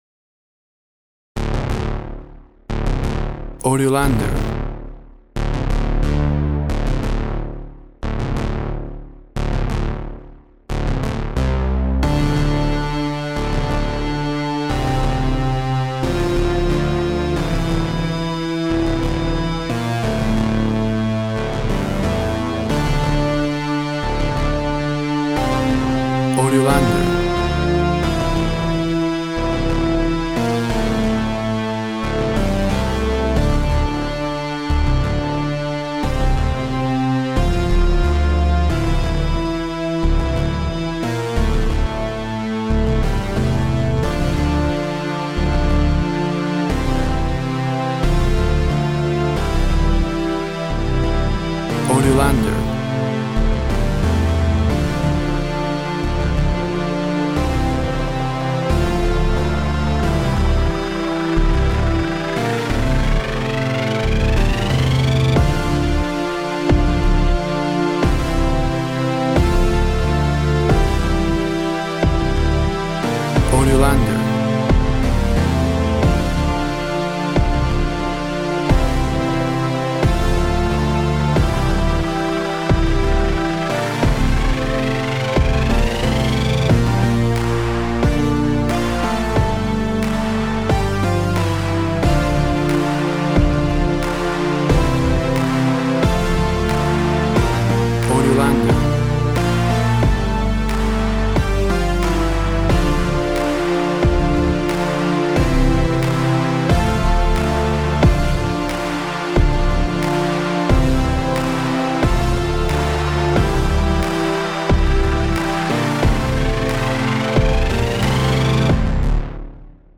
A Synth driven soundtrack influenced by 1980’s movies.
Tempo (BPM) 90